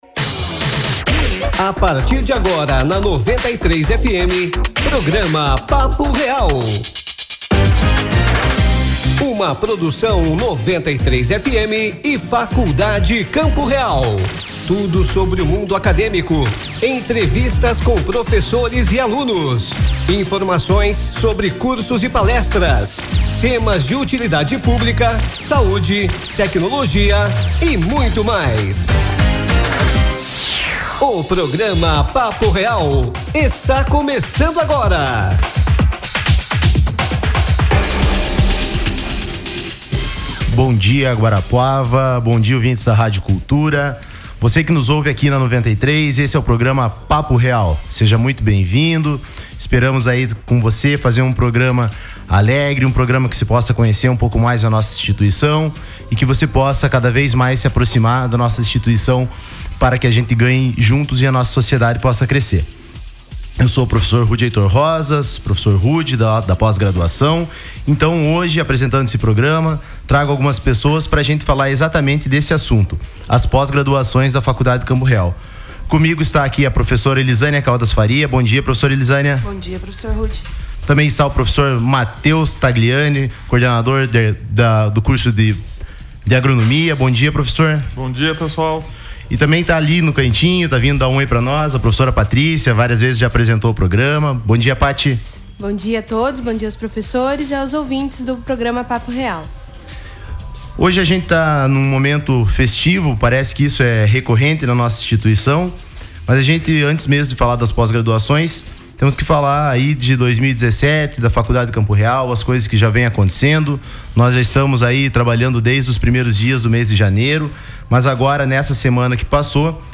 O Programa Papo Real acontece todos os sábados das 9h30 às 10h, na Rádio Cultura FM 93,7, com entrevistas de professores e alunos, informações sobre cursos, dicas de saúde, tecnologia e utilidade pública.